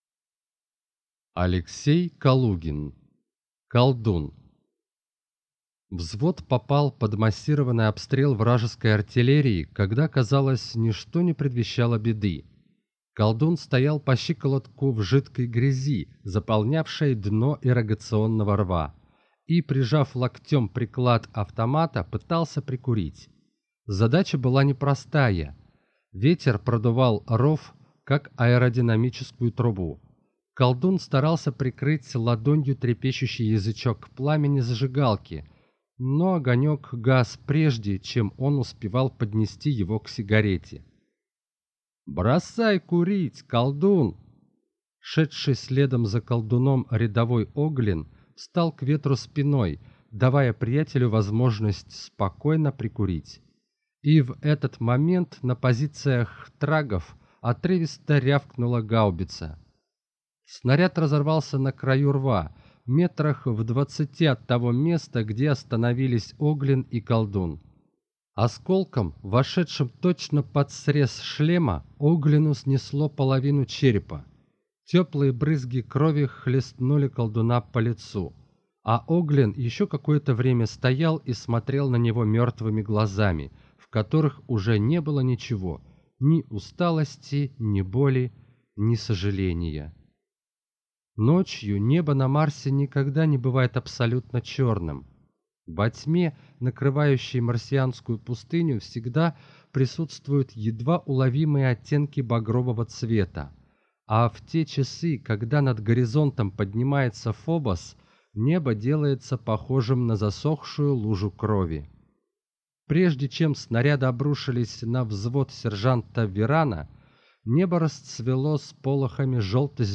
Аудиокнига Колдун | Библиотека аудиокниг